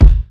Kick11.wav